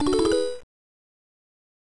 buying upgrade.wav